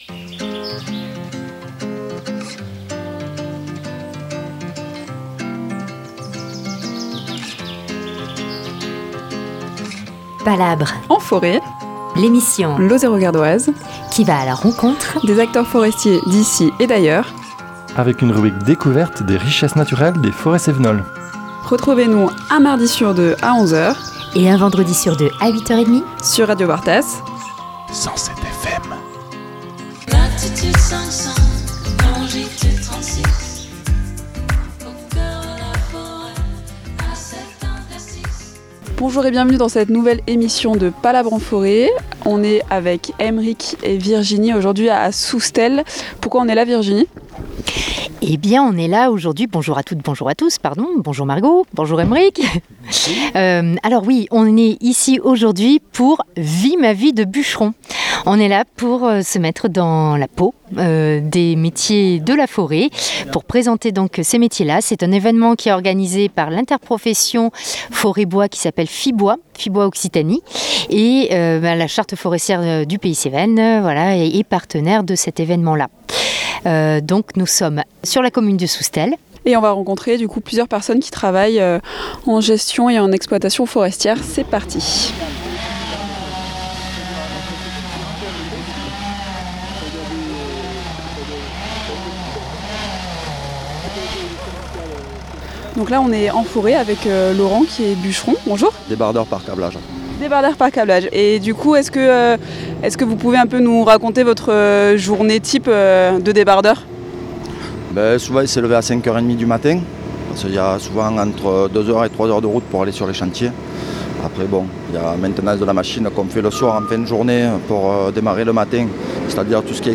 Rendez-vous à Soustelle pour ce nouvel épisode de Palabres en forêt. Dans le cadre de l’événement Vie ma vie de bucheron, nous partons en forêt à la rencontre de personnes travaillant dans l’exploitation forestière.